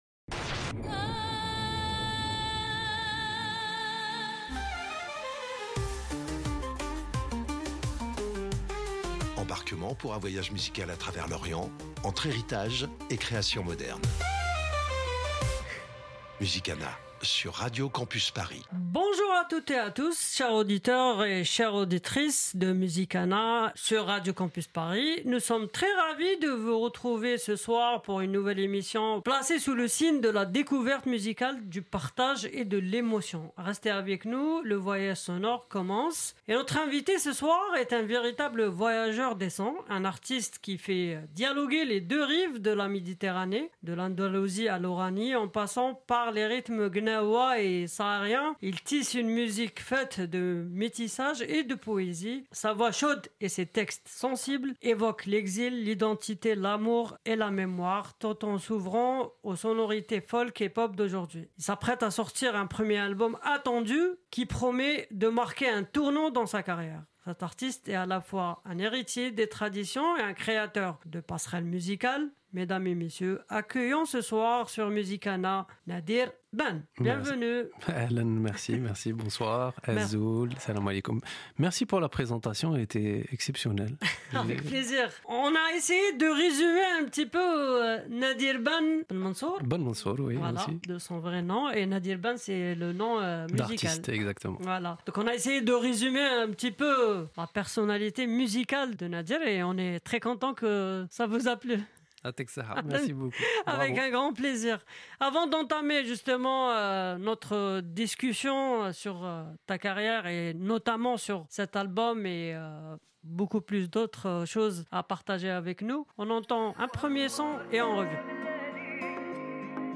Type Musicale